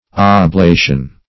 Oblation \Ob*la"tion\, n. [L. oblatio: cf. F. oblation.